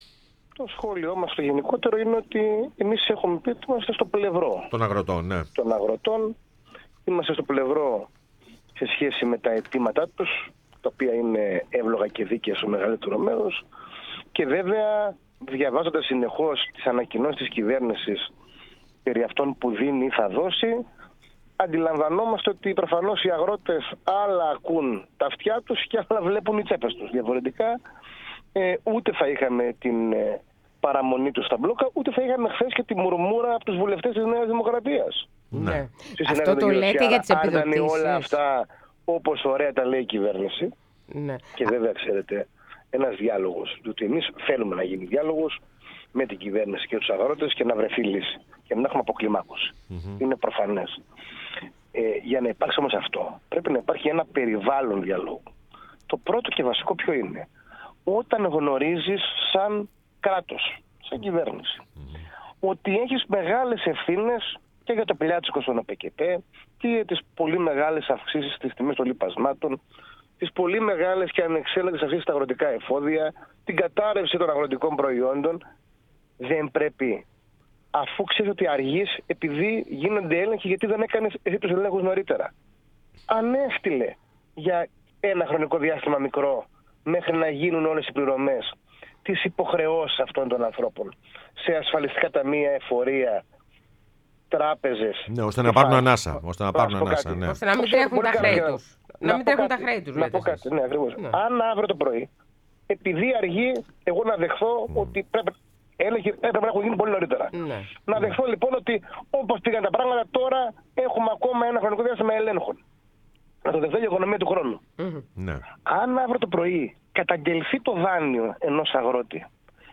Ο Κώστας Τσουκαλάς εκπρόσωπος Τύπου ΠΑΣΟΚ μίλησε στην εκπομπή Πρωινές Διαδρομές